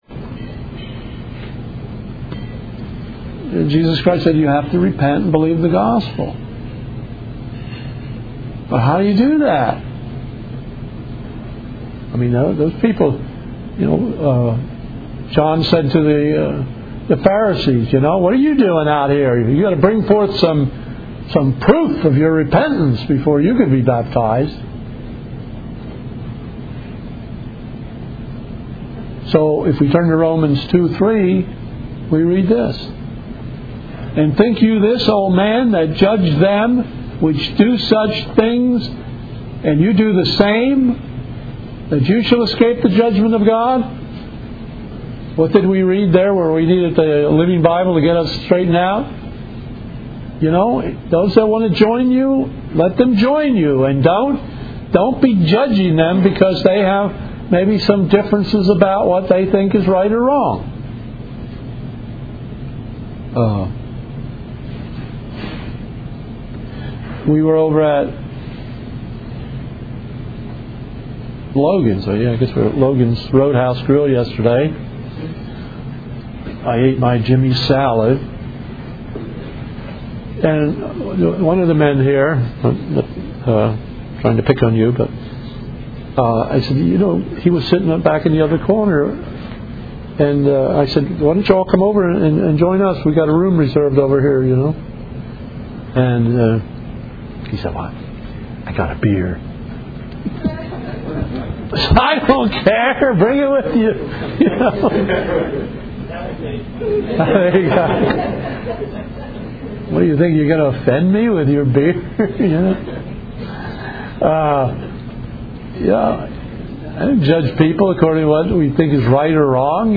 Mobile 2009 Conference - The One God part 2